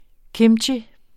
Udtale [ ˈkemtji ]